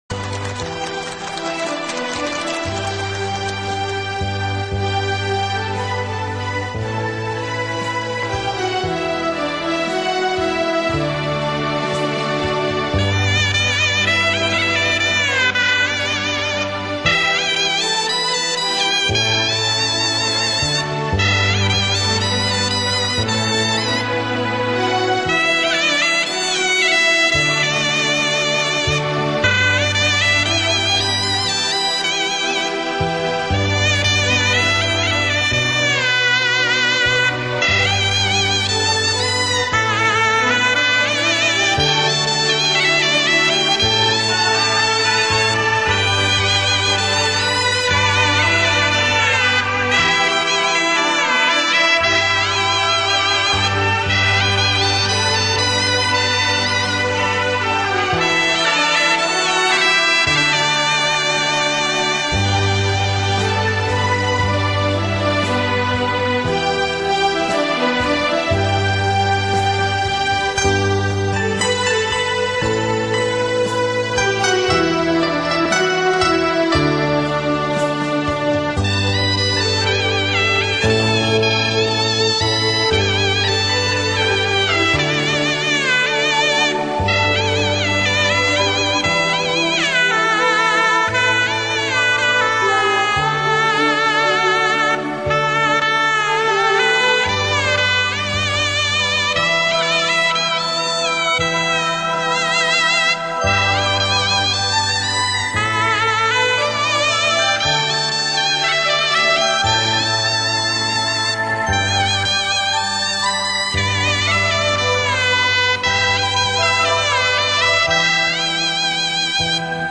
回复: 再求锁呐曲《十送红军》（纯音乐版）[已解决]
唢呐